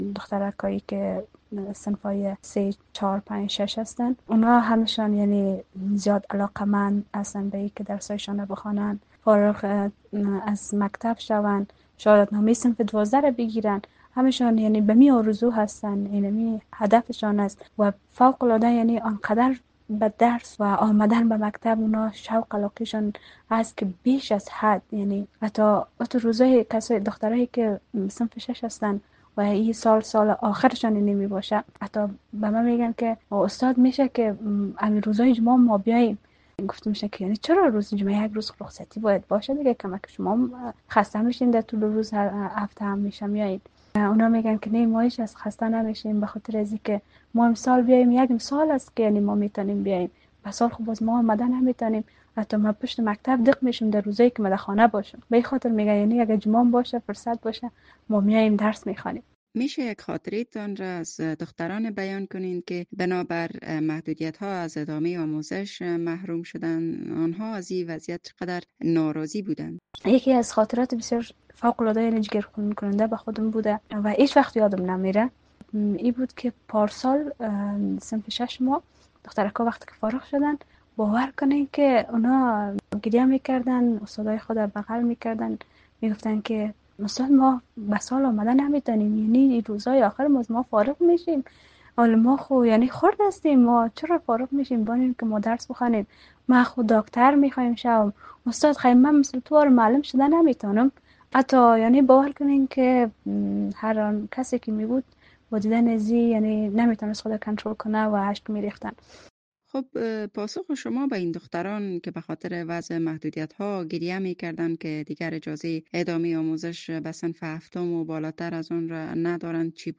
مصاحبه با استاد یکی از مکاتب کابل